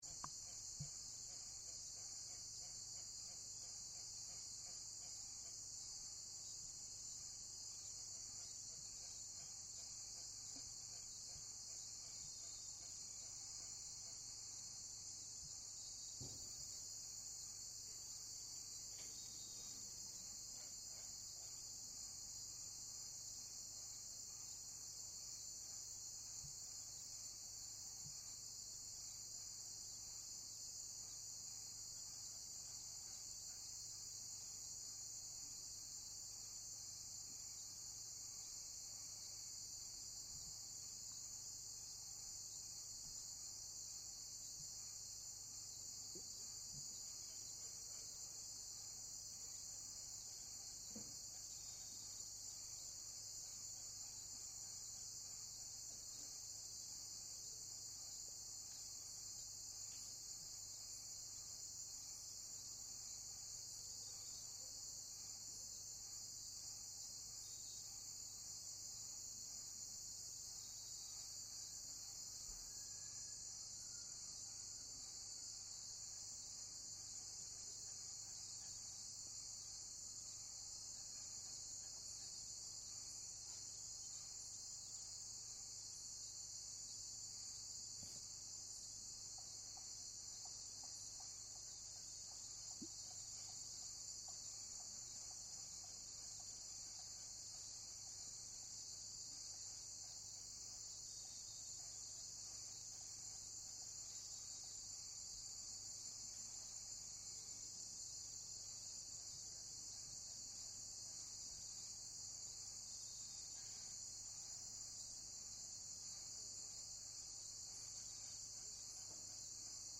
Au milieu du parc national Madidi, depuis Rurrenabaque, j’ai rejoint un magnifique campement pour aller observer les animaux dans la jungle.
Ambiance sonore, la nuit au bord du lac :